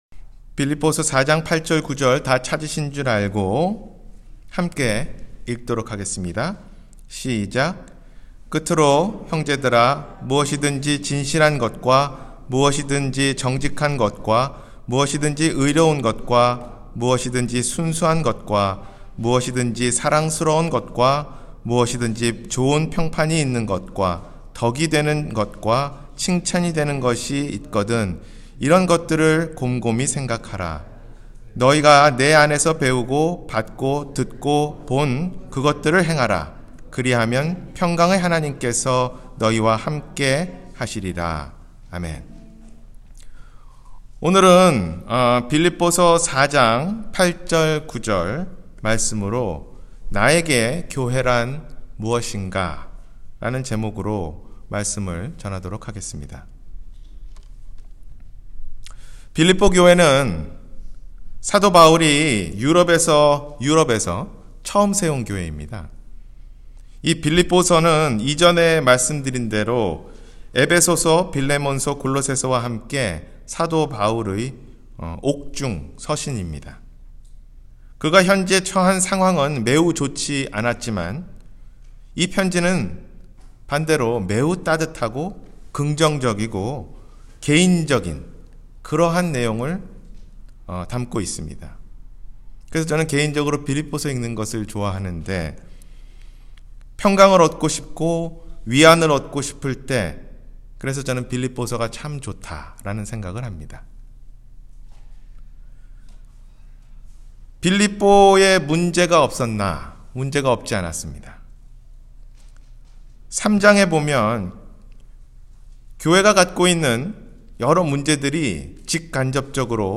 나에게 교회란 무엇인가-주일설교